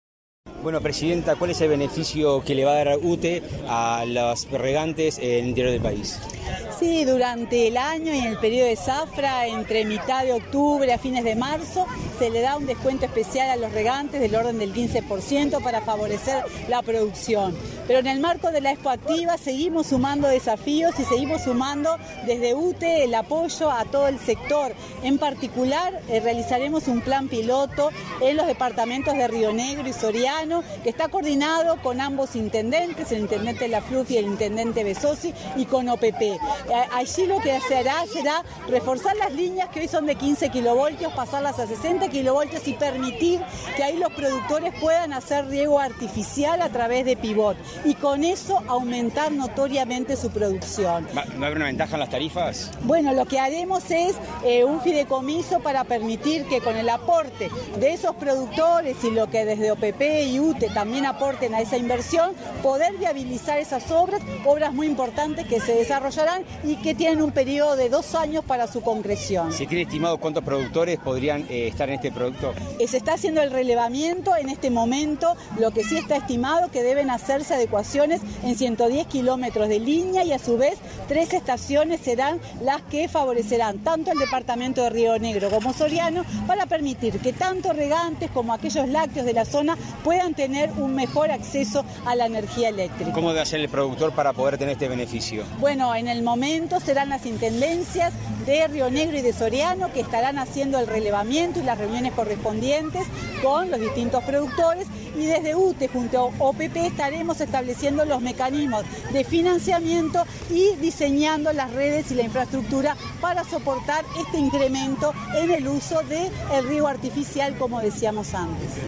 Declaraciones de la presidenta de UTE, Silvia Emaldi